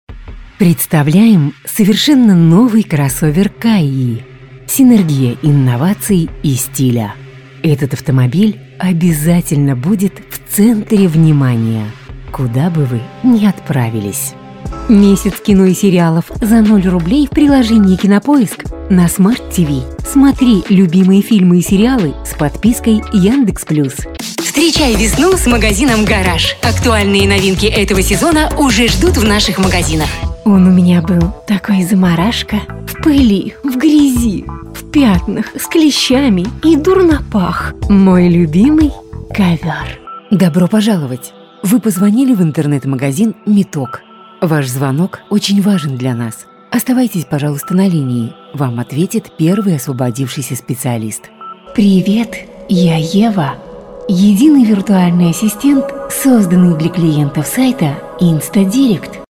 Тракт: AKG P420, Yamaha AG03, акустический экран.